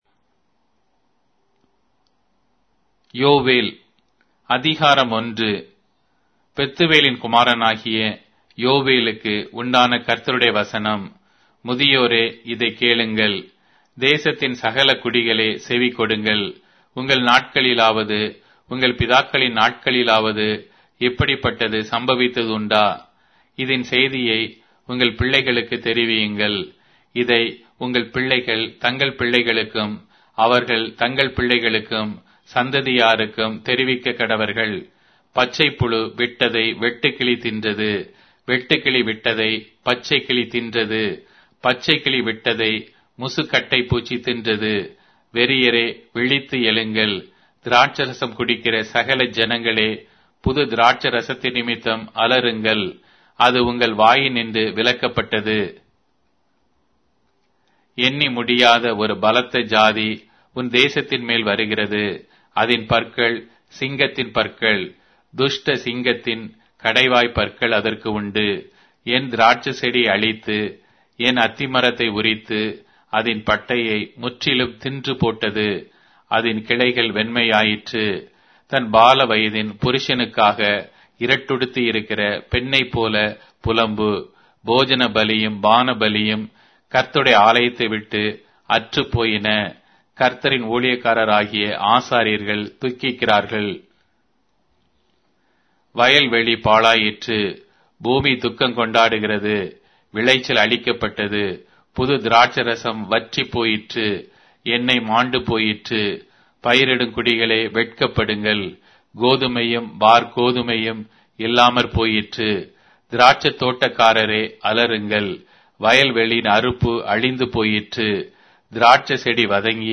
Tamil Audio Bible - Joel 3 in Wlc bible version